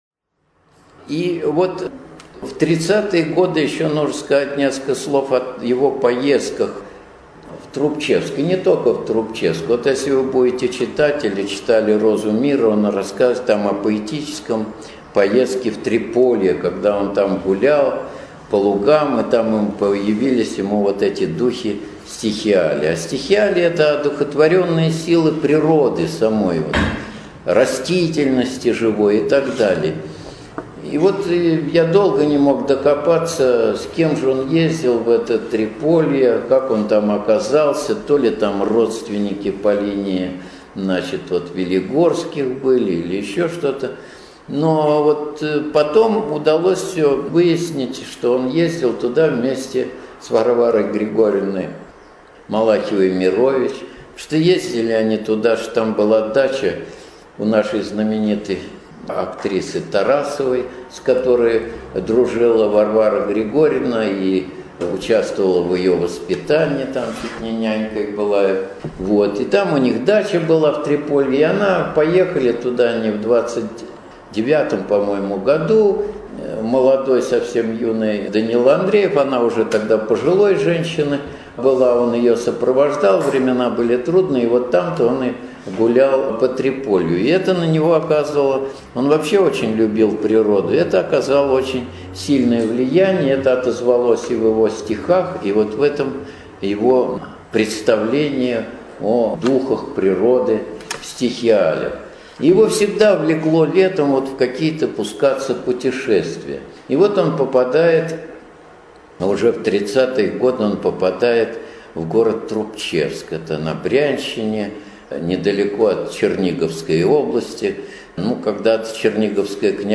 Библиотека им. В. Розанова, г. Сергиев Посад